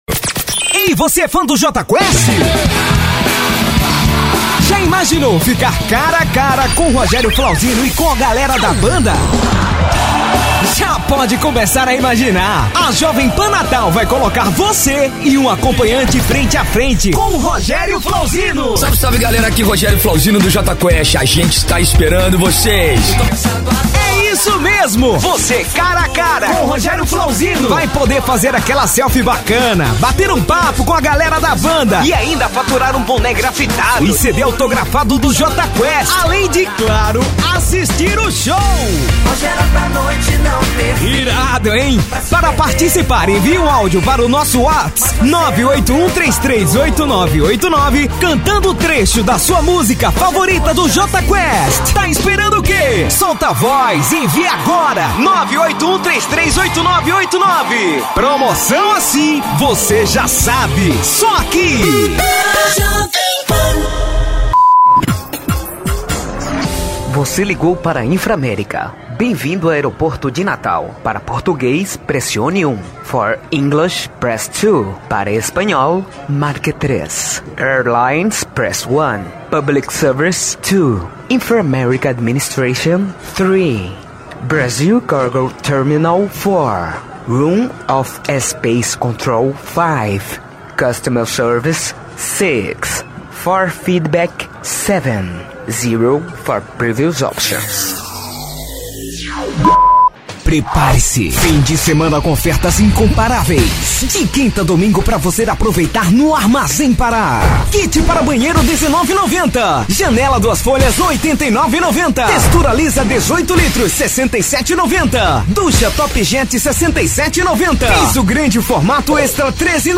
Spot Comercial
Vinhetas
Animada
Caricata
Muito bom, só não precisaria esticar todo final de frase. Parabéns pelo trabalho.